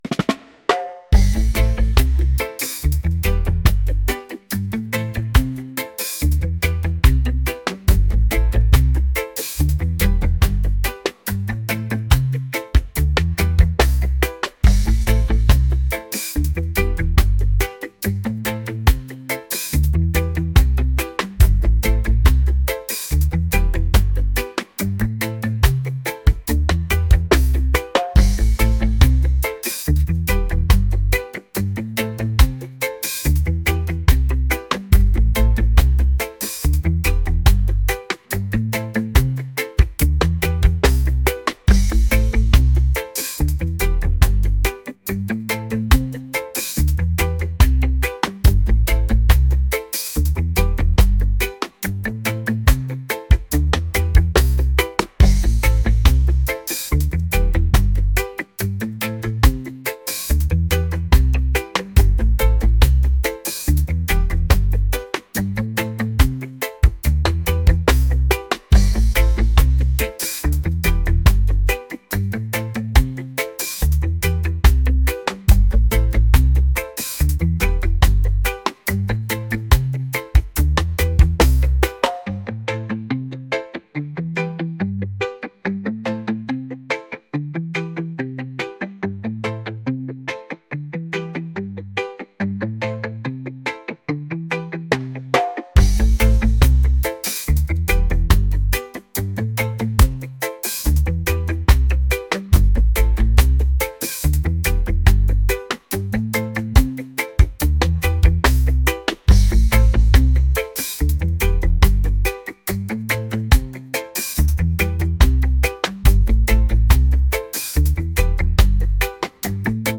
reggae | pop | lounge